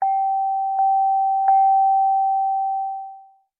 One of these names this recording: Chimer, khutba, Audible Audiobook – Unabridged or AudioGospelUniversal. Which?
Chimer